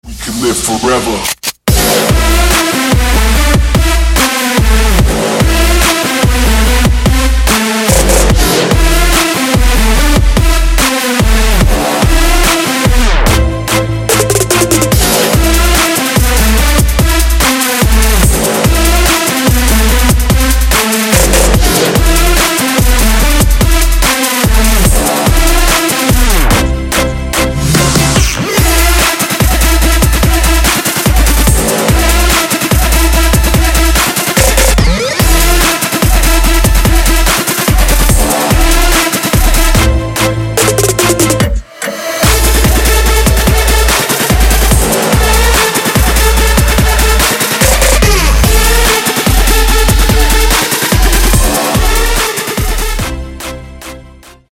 Trap
трэп